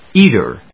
音節éat・er 発音記号・読み方
/‐ṭɚ(米国英語), ‐tə(英国英語)/